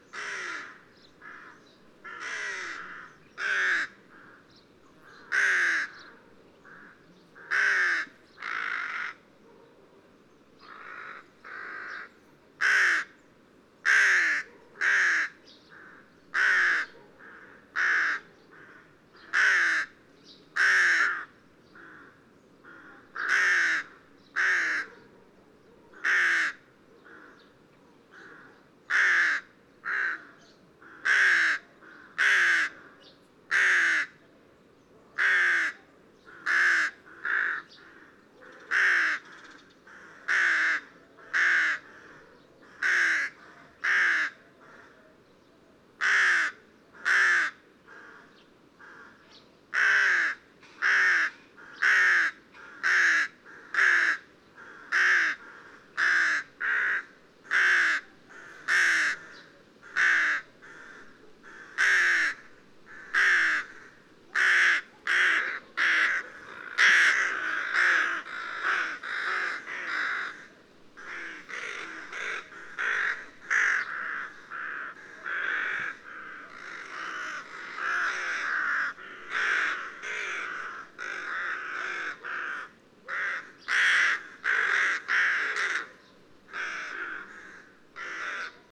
PFR09565, 2-10, 130610, Rook Corvus frugilegus pastinator, colony,
Songino Khairkan Uul below, Mongolia